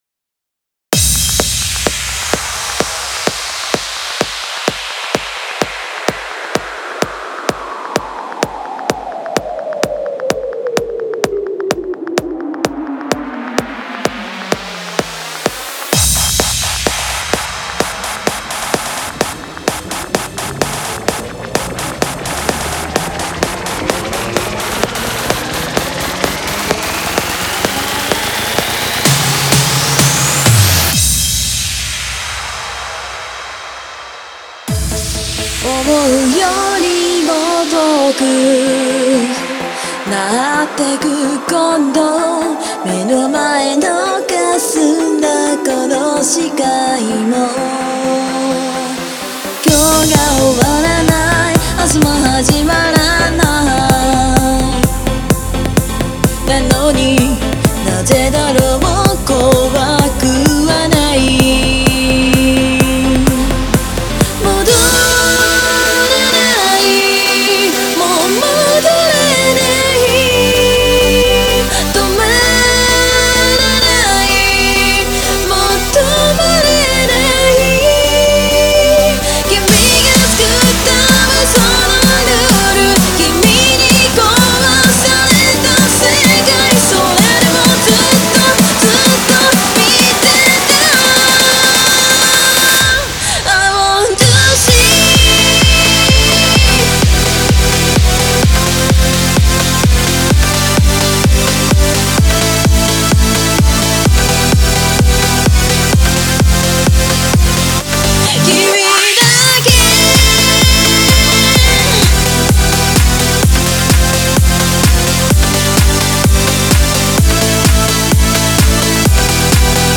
data/music/Japanese/J-Core